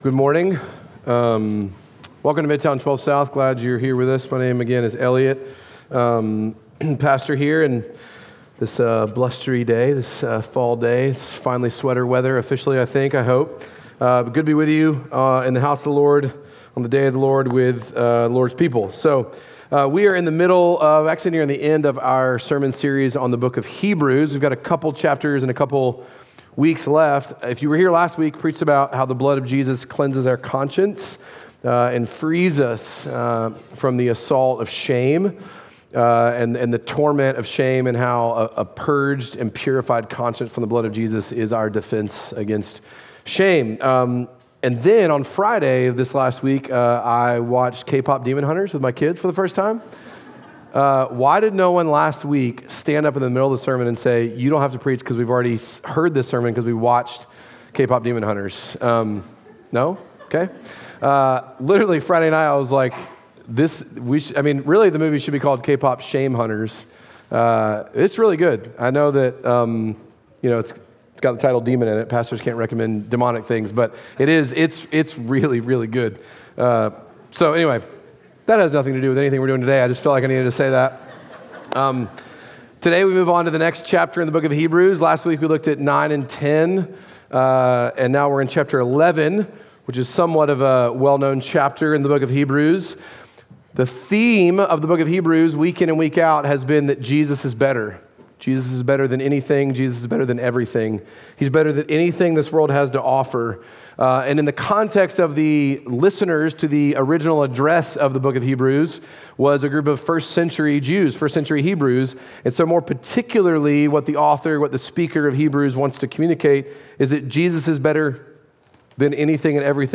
Midtown Fellowship 12 South Sermons A Better City Nov 09 2025 | 00:45:34 1x 00:00 / 00:45:34 Subscribe Share Apple Podcasts Spotify Overcast RSS Feed Share Link Embed